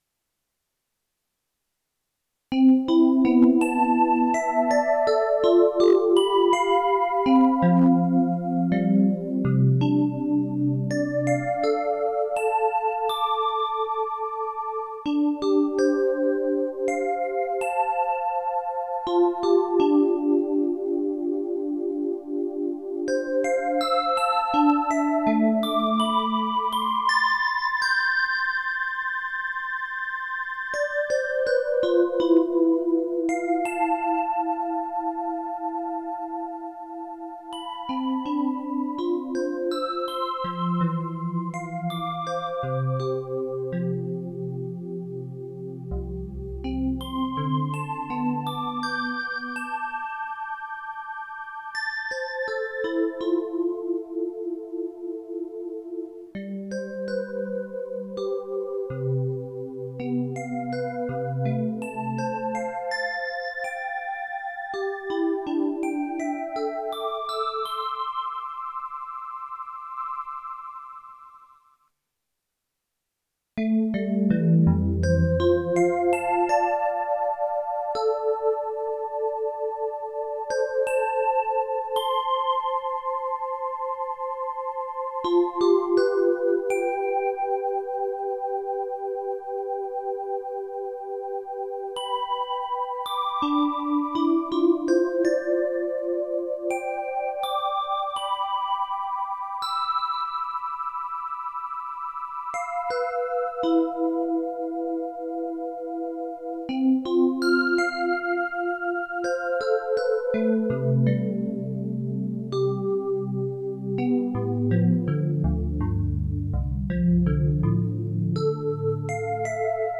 Lépicia enregistrée dans notre jardin botanique au couvet des fleurs à Saint Maximin le 27 octobre 2024 à partir de 15h.15